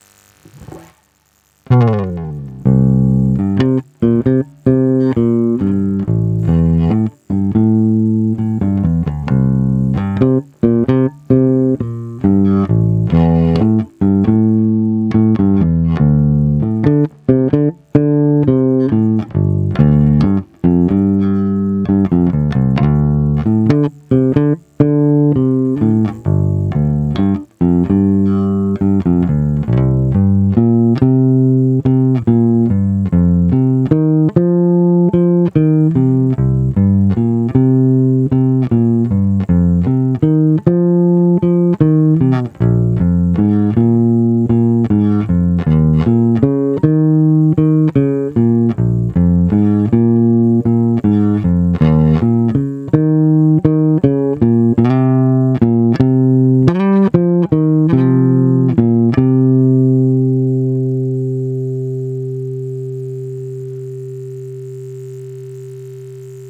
Nahrávky s hlazenkama D´addario chromes ECB81:
Hlazenky na plno